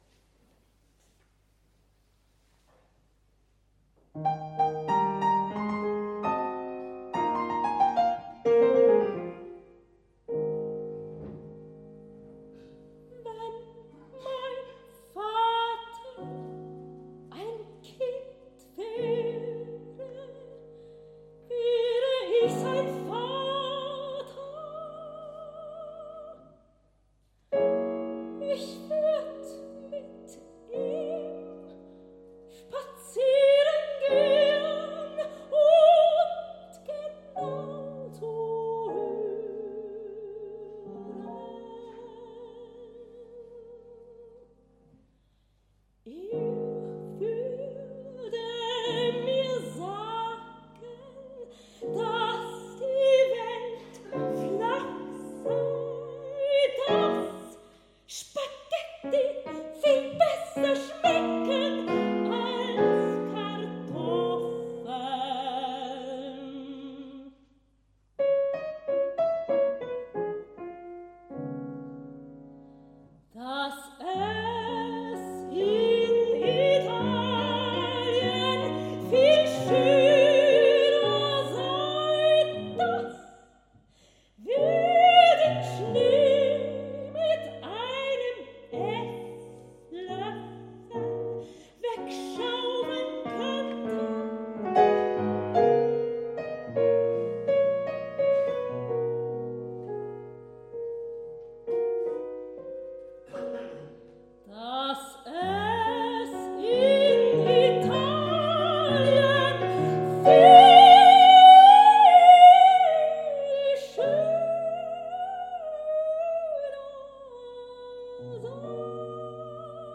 für Sopran und Klavier